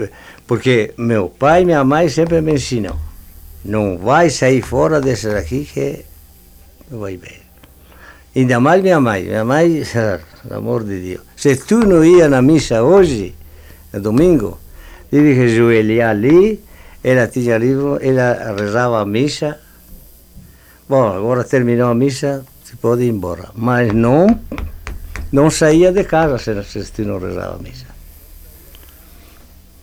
Item AUD - Trecho de aúdio da entrevista
Unidade Banco de Memória Oral